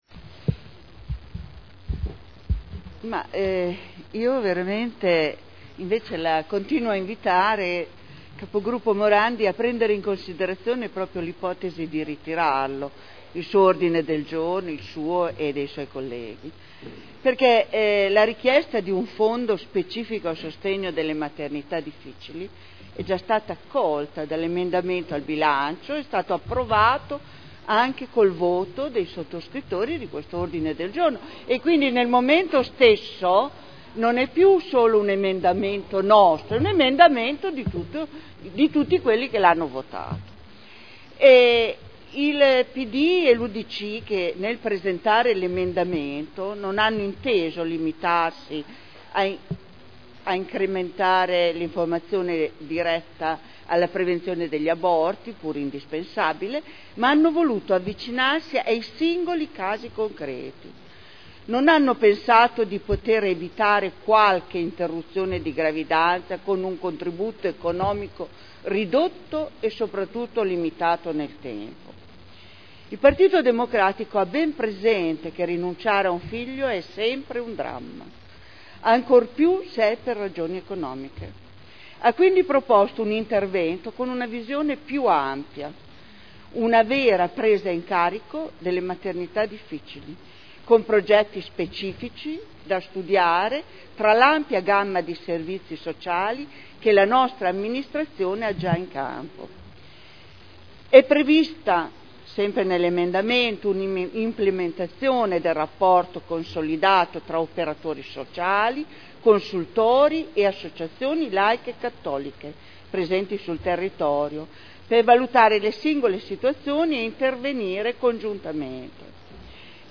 Franca Gorrieri — Sito Audio Consiglio Comunale